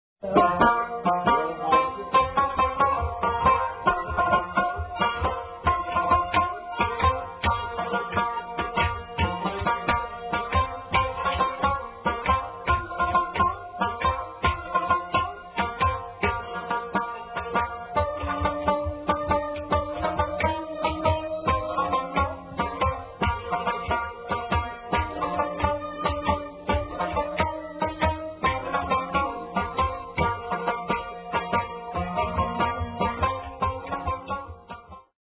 Rebab solo accompanied by sarangi - 2:12